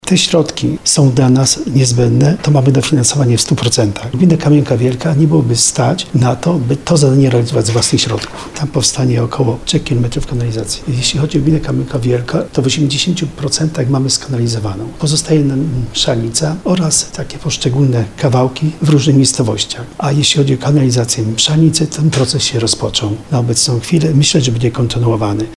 Proces realizacji kanalizacji w Mszalnicy już się rozpoczął – mówi wójt Andrzej Stanek.